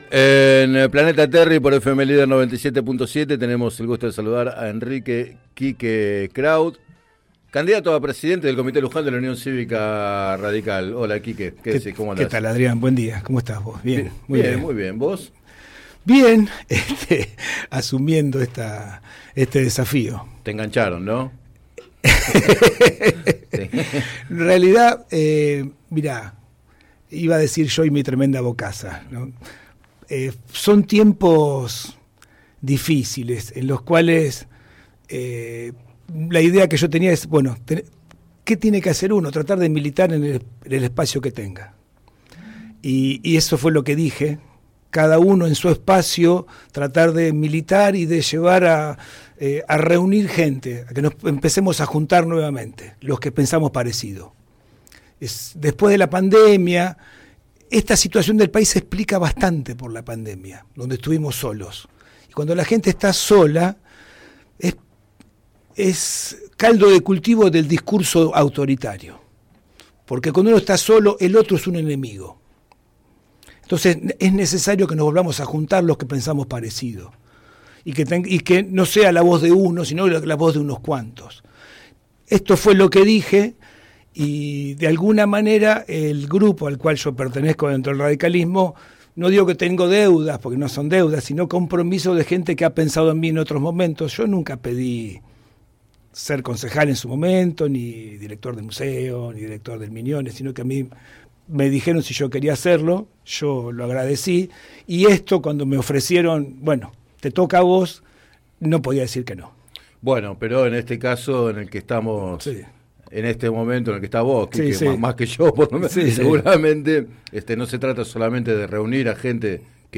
Entrevistado en el programa Planeta Terri